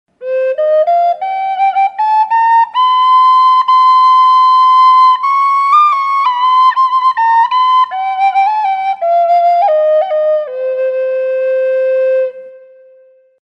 key of C
highC sedona.mp3